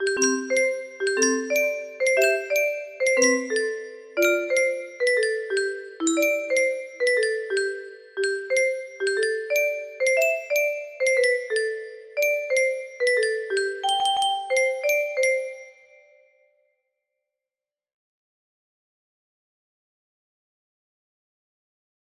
30116 music box melody